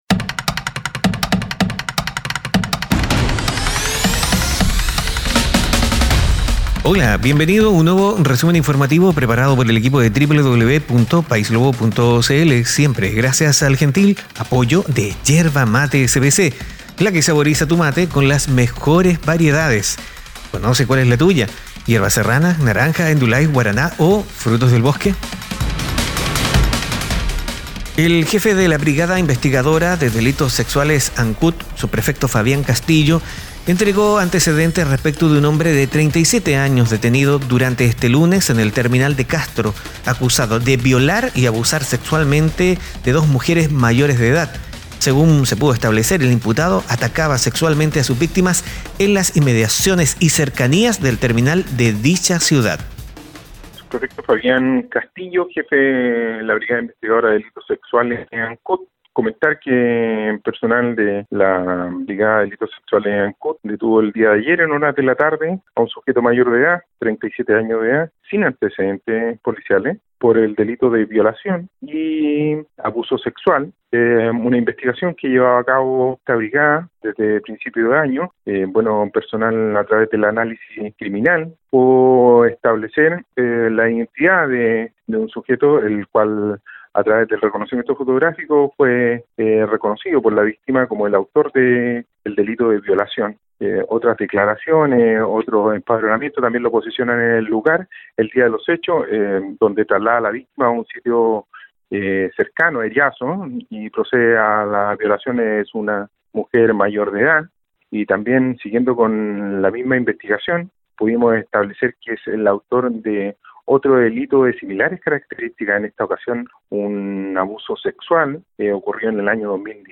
Noticias e informaciones en pocos minutos.